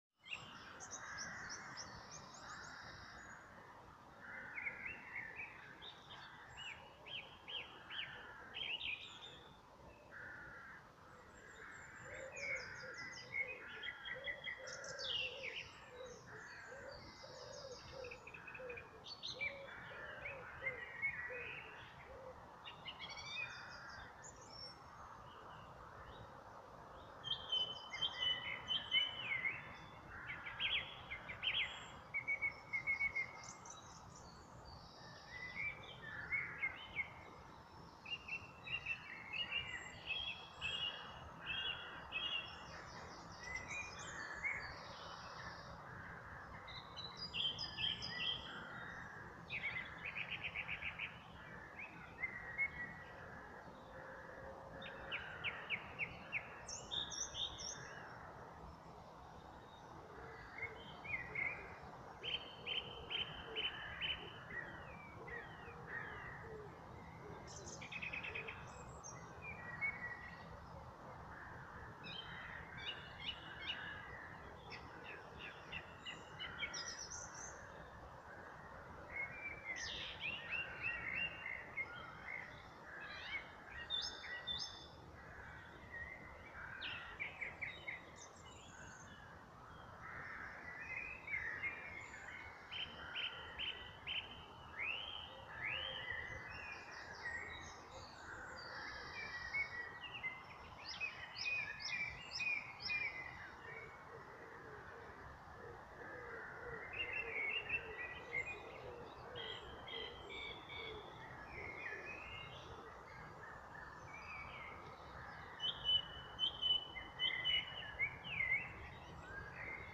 Our holiday home, Hop Cottage, was situated at the end of a half mile unpaved road, set among trees alongside a couple of other cottages.
But, each morning, we awoke to one singing his heart out, perched high in the early morning sun. I thought I’d try my luck recording his song on my smart phone.
song-thrush.mp3